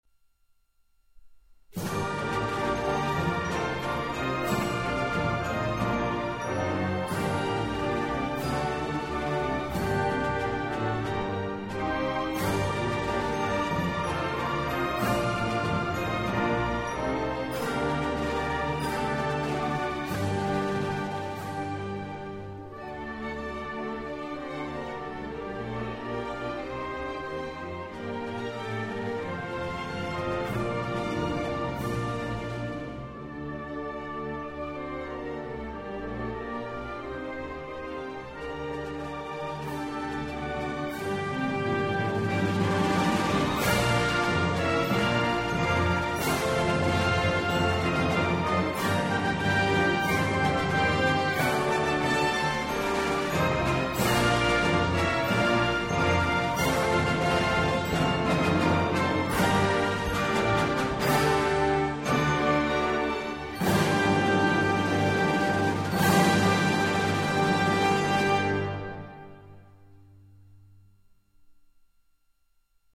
Гімн України без слів mp3